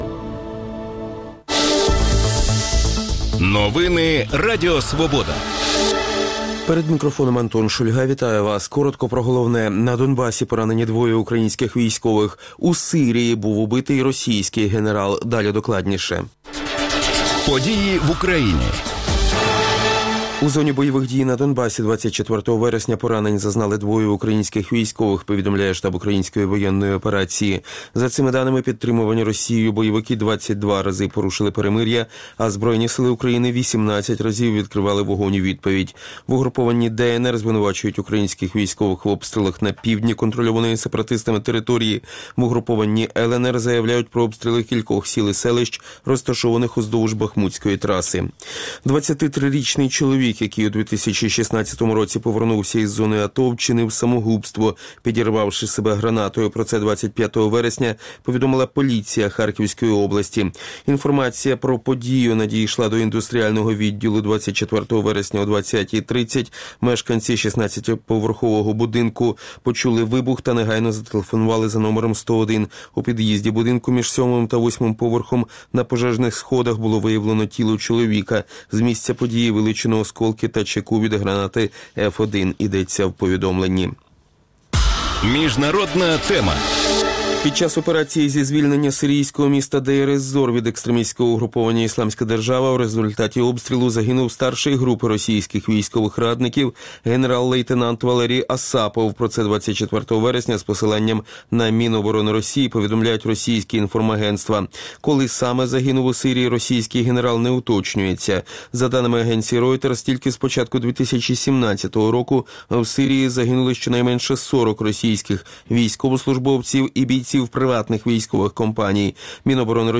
Як планують фінансувати основні статті кошторису держави на наступний рік? Дискутуватимуть про це в ефірі Радіо Свобода народні депутати: від «Самопомочі» – Тетяна Острікова і від БПП – Сергій Рудик.